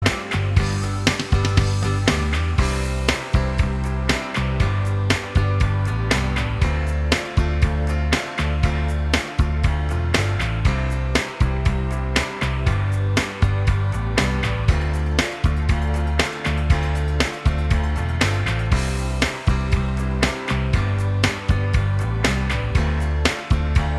Minus All Guitars Pop (1990s) 4:03 Buy £1.50